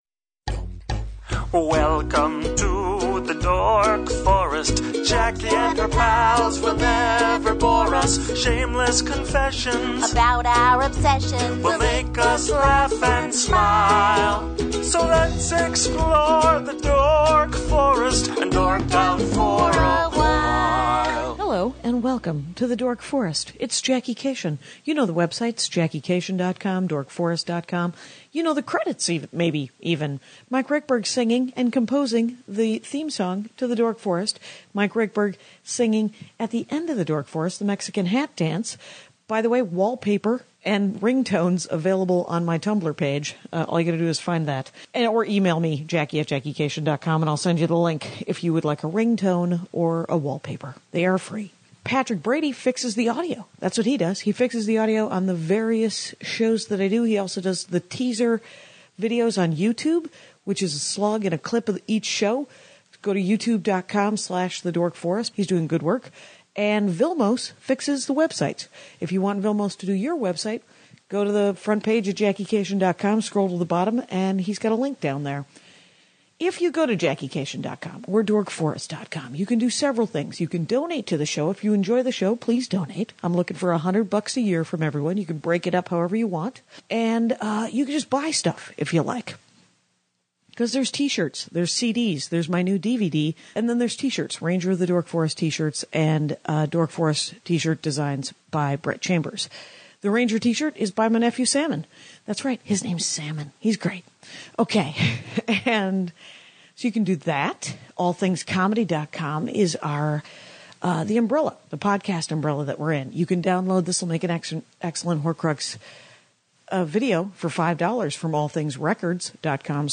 She reads HER OWN AD.